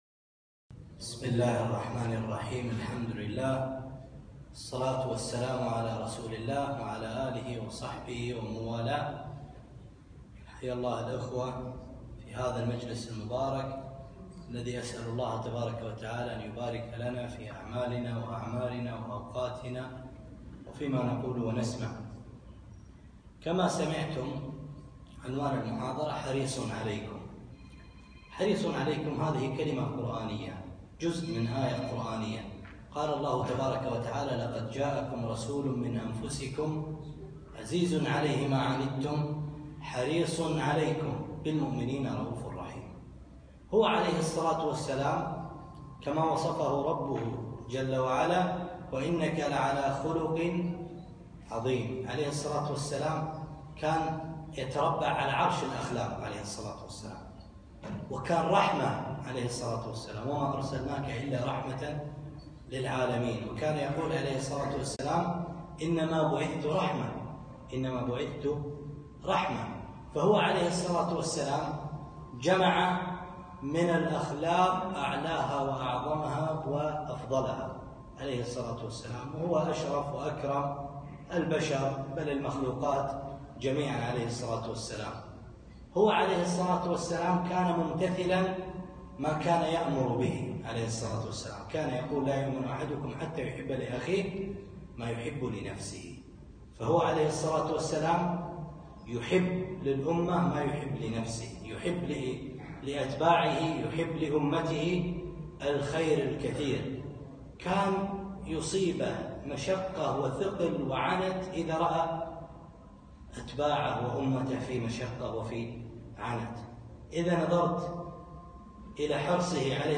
محاضرة - حريص عليكم